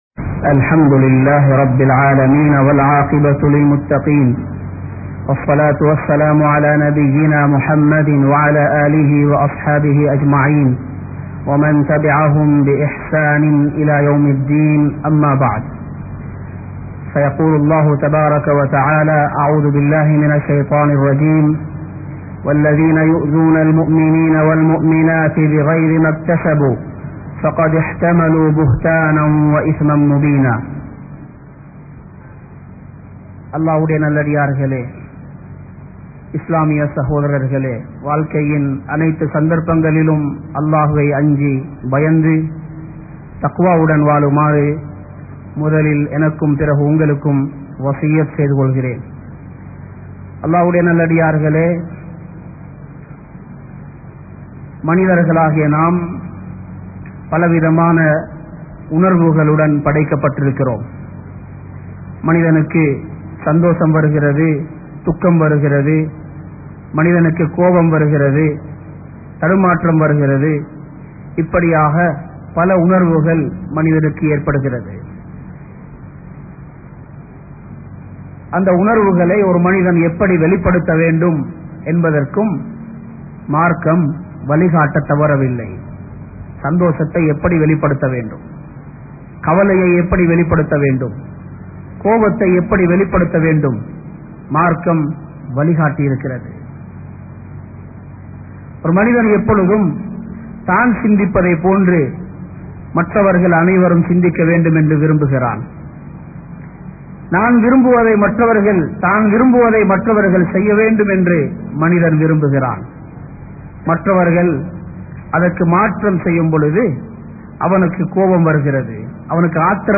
Mattravarhalai Vimarsikkaatheerhal (மற்றவர்களை விமர்சிக்காதீர்கள்) | Audio Bayans | All Ceylon Muslim Youth Community | Addalaichenai
Kollupitty Jumua Masjith